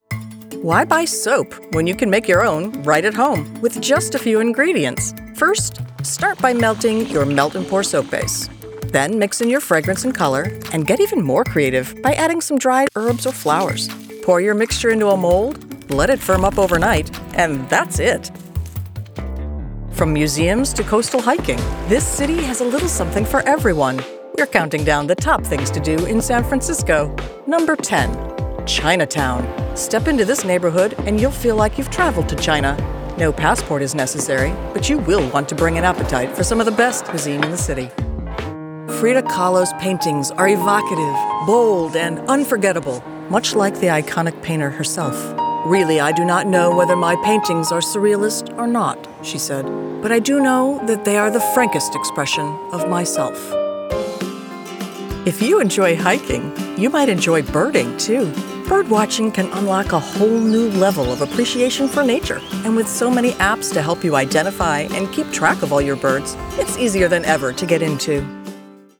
Middle Aged
Whether you’re looking for a warm, approachable tone for a commercial, a polished, authoritative voice for corporate narration, or a nuanced character for an audio book, I can provide a voice right for your project.